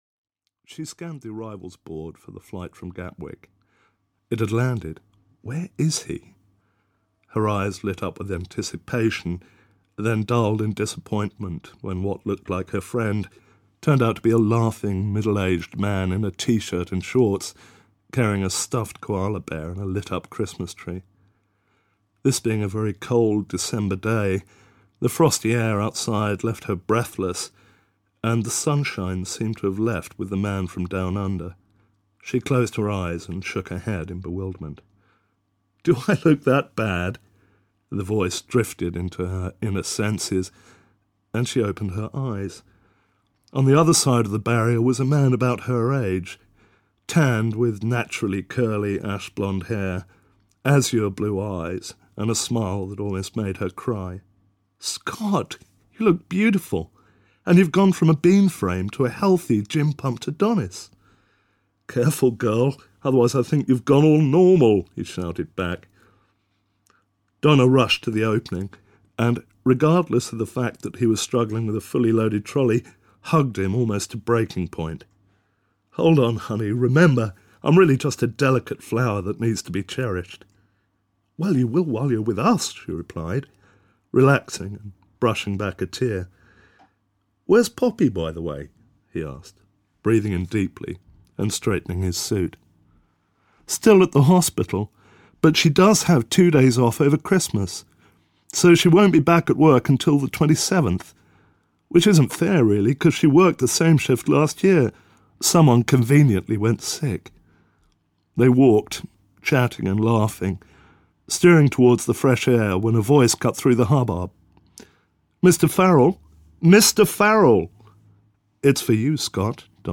Cry Destiny (EN) audiokniha
Ukázka z knihy